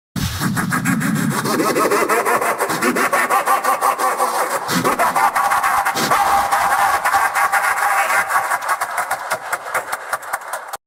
Sonic.omt Laugh